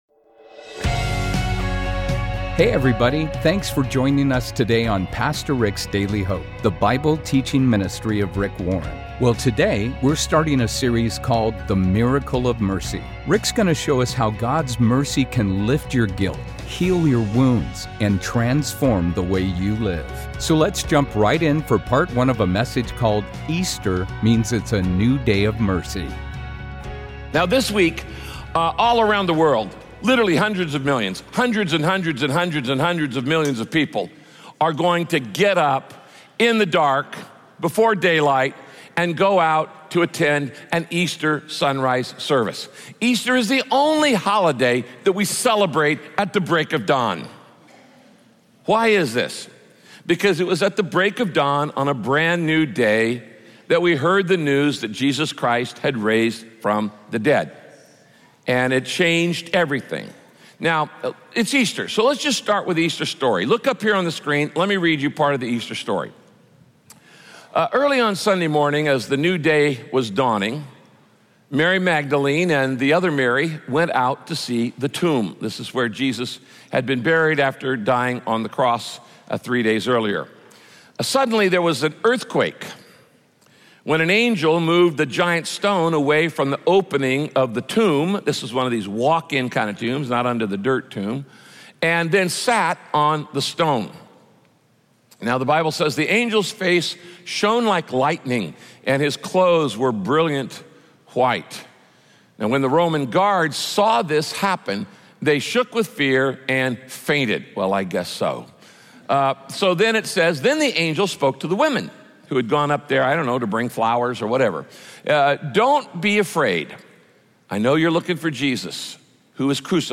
In this message, Pastor Rick shares stories of mercy from God's Word and teaches how those stories relate to your life.